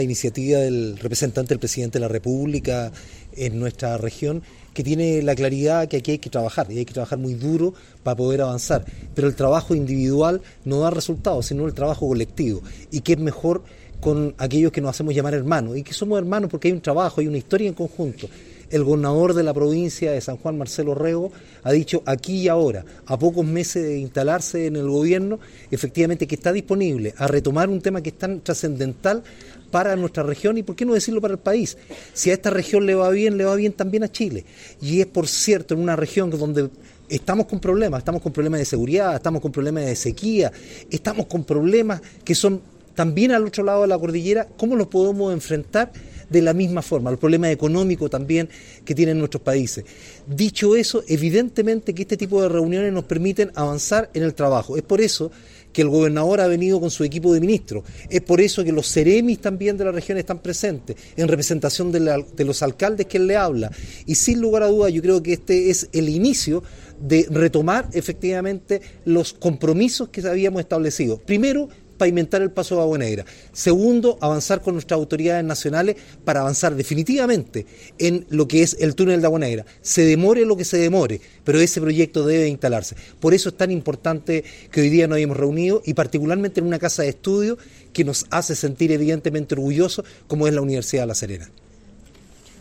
En ese sentido, el alcalde de Vicuña y además, Presidente de la Asociación de municipios de la región, Rafael Vera, manifestó que
ENCUENTRO-BINACIONAL-Rafel-Vera-Alcalde-de-Vicuna.mp3